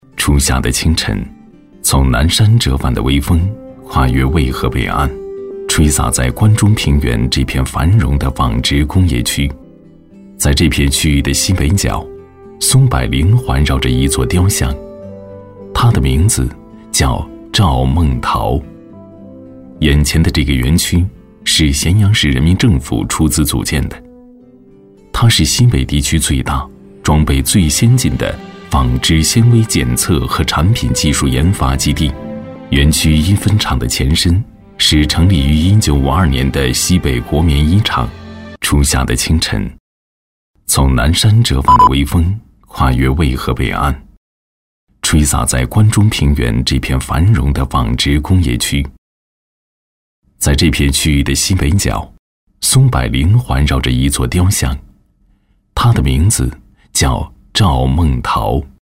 娓娓道来 讲述
大气高端，品质男音，擅长现在科技感配音，地产品质配音，宣传片配音，旁白等。